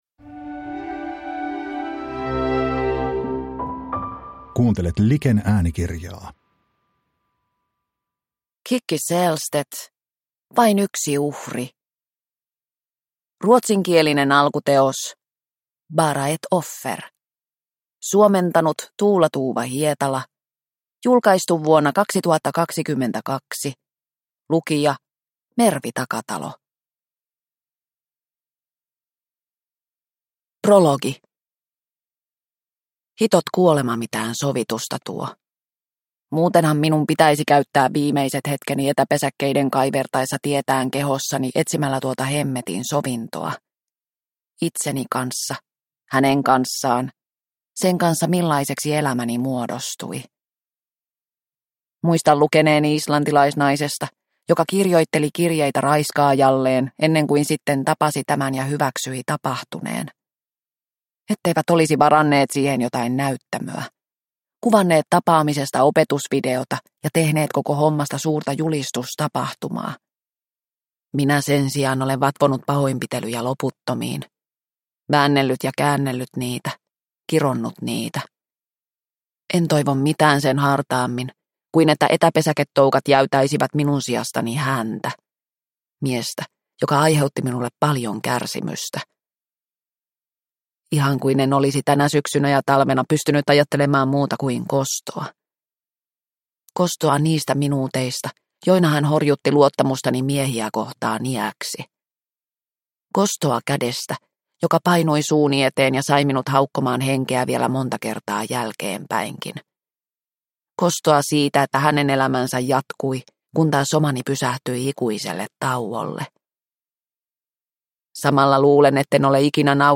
Vain yksi uhri – Ljudbok – Laddas ner